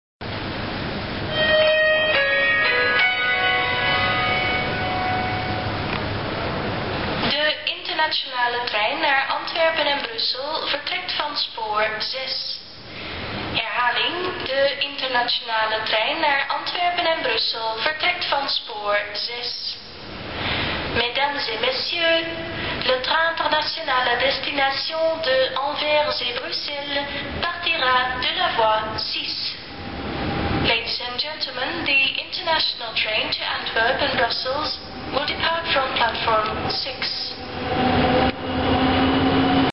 Nee stationsomroep.